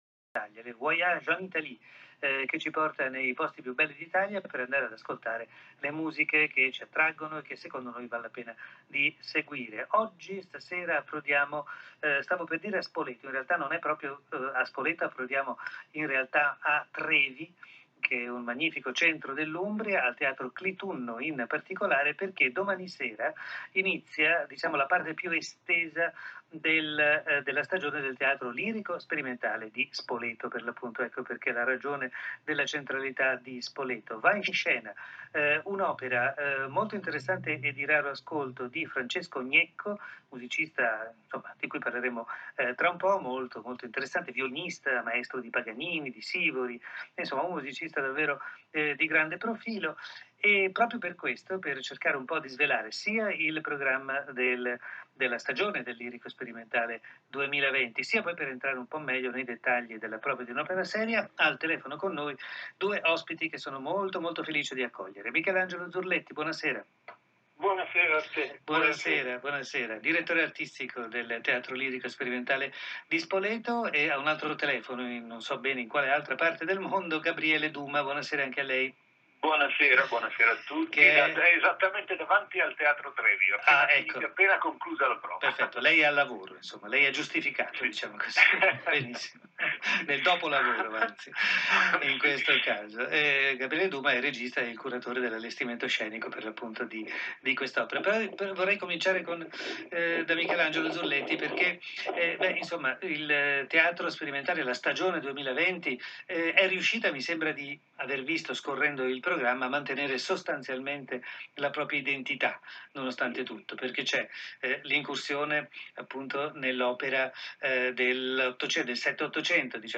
Direzione Musicale e pianoforte
Cantanti solisti